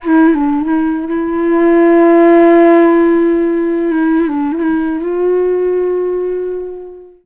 FLUTE-B01 -R.wav